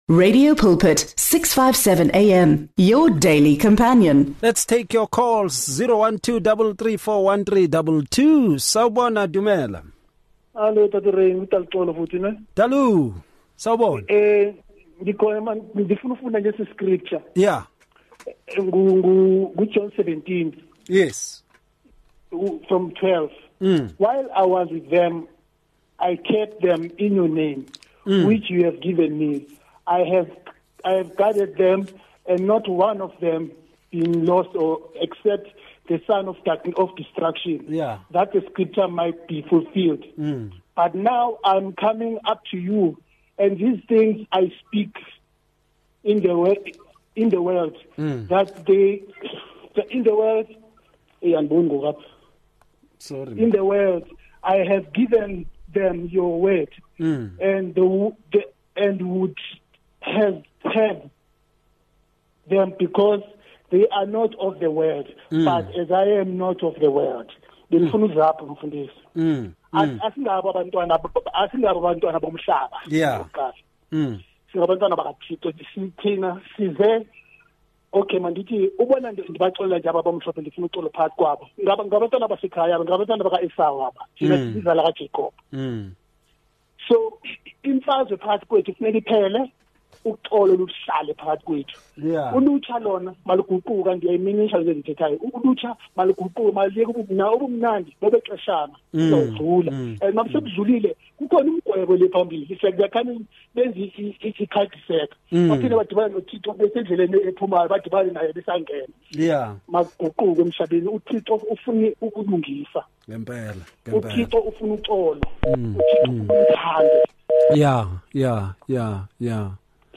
Their conversations typically emphasize practical Christian living, relying on Scripture to understand how believers can stand firm against spiritual adversaries. This series draws from passages like Ephesians 6:10-18, which describe the armor of God as essential for spiritual defense.